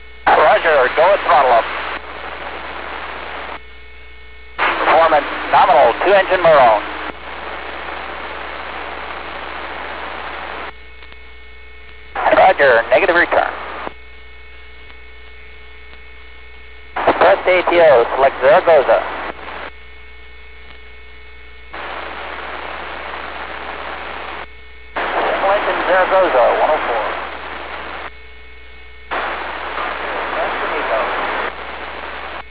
Recordings of Space Ships and Satellites